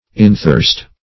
Inthirst \In*thirst"\